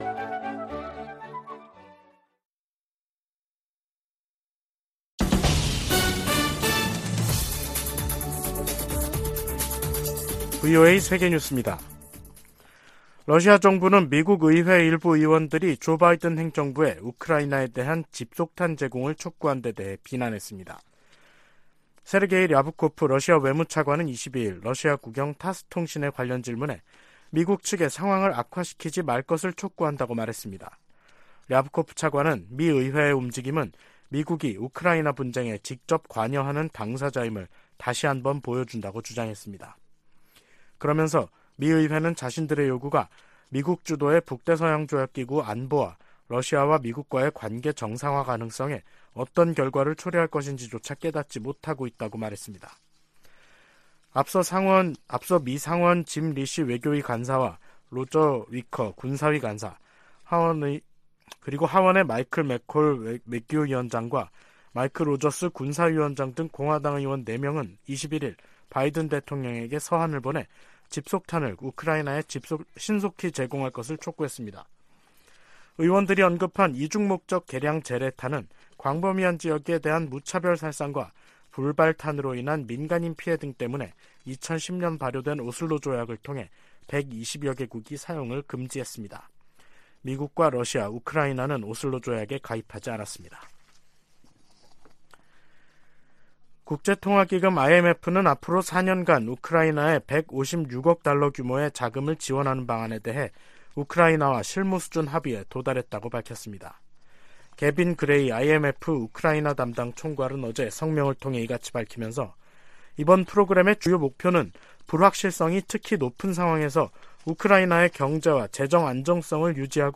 VOA 한국어 간판 뉴스 프로그램 '뉴스 투데이', 2023년 3월 22일 3부 방송입니다. 북한이 또 다시 순항미사일 여러 발을 동해상으로 발사했습니다. 미국 정부는 모의 전술핵 실험에 성공했다는 북한의 주장에 우려를 표했습니다. 미국 국방부가 북한의 미사일 프로그램이 제기하는 도전을 면밀히 감시하고 있다고 강조했습니다.